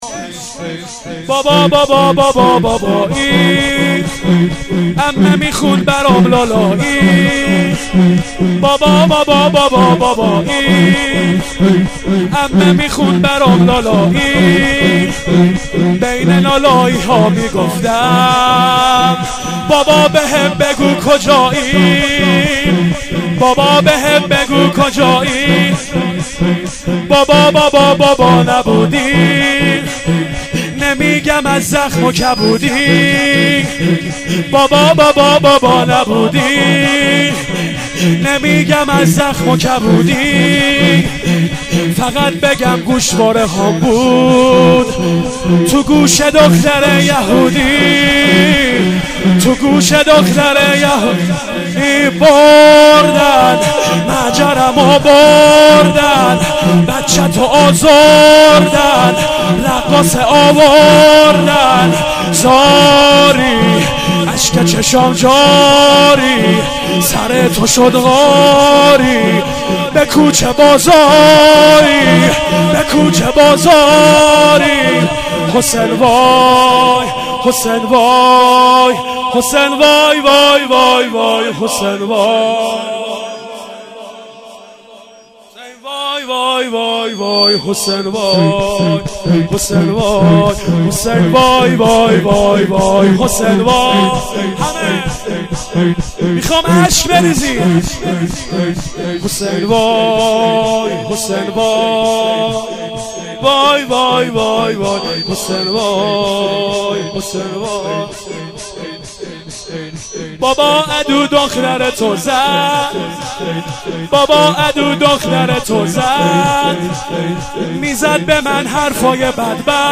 بابا بابا بابايي ... شور و حروله و لطمه زني ...
مداحی ذاکر اهل بیت